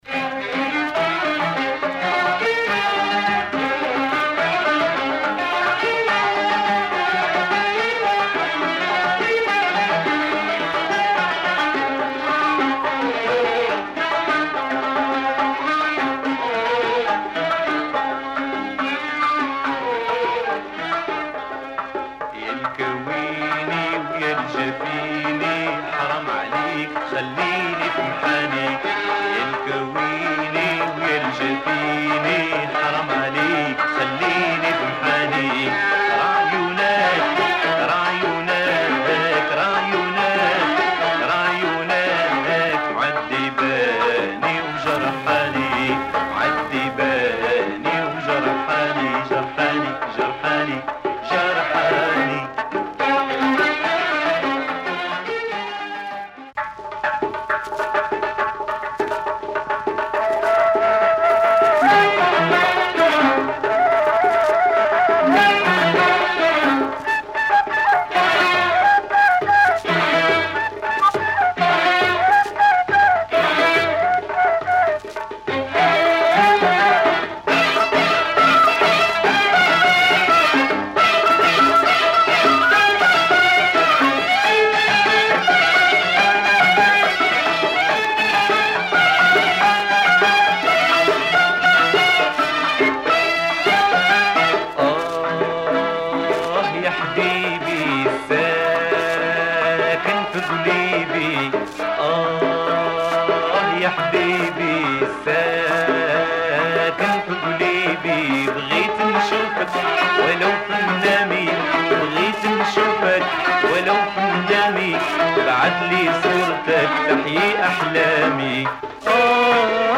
Moroccan Mizrahi music